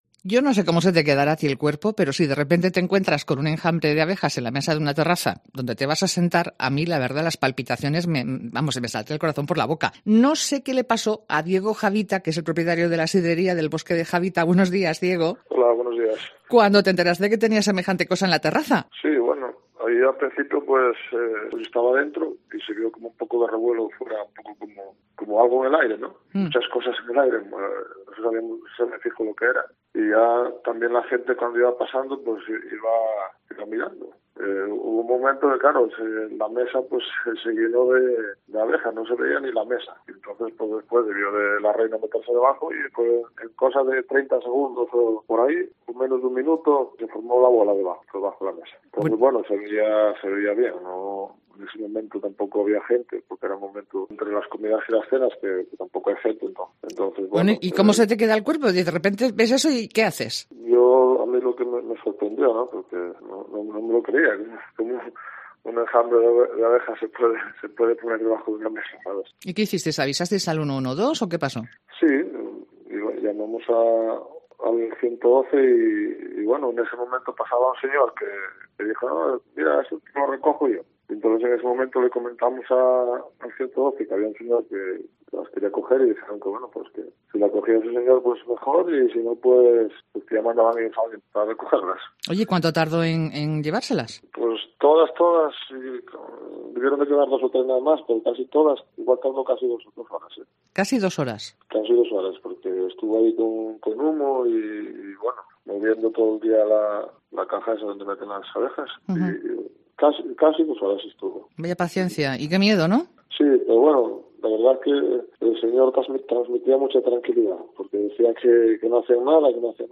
Según los expertos, es algo más llamativo que peligroso, ya que es relativamente habitual en esta época del año. En 'Herrera en COPE Asturias', el propietario del negocio nos ha contado como han vivido esos momentos y un apicultor los consejos y las claves para afrontar este tipo de situaciones.